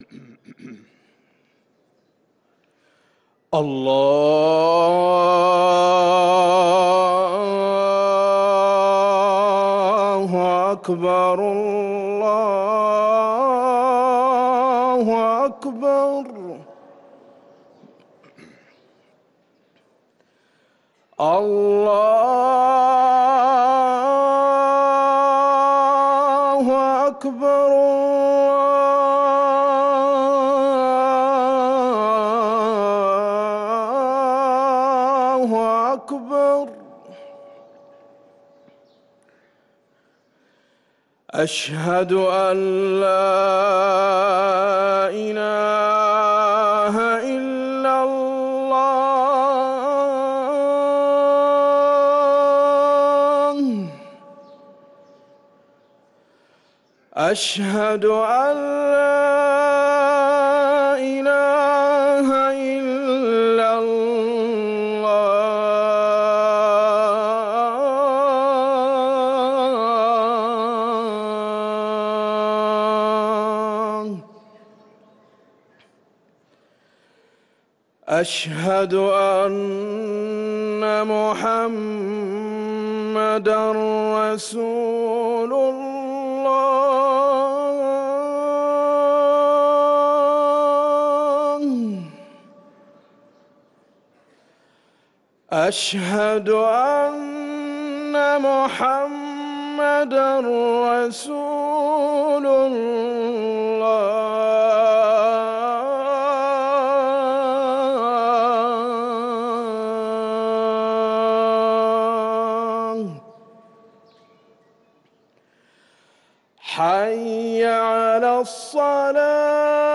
أذان العصر للمؤذن